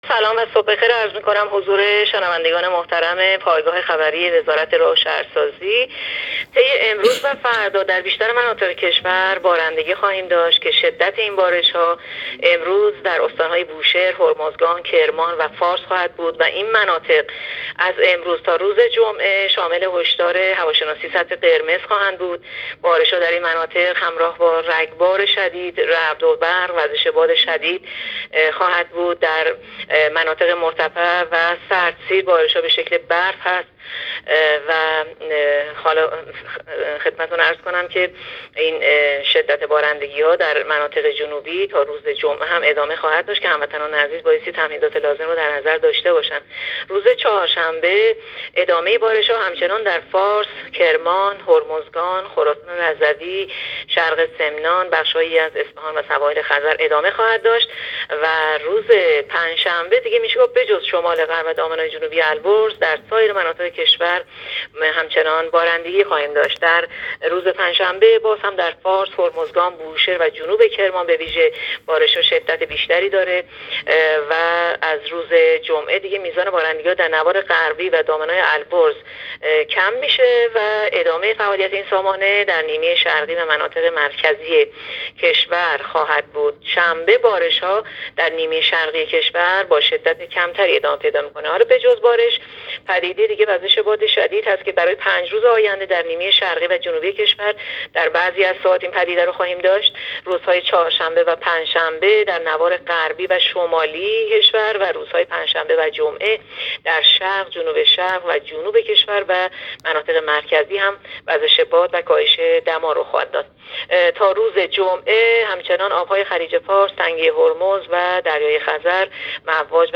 گزارش رادیو اینترنتی پایگاه‌ خبری از آخرین وضعیت آب‌وهوای ۲۵ آذر؛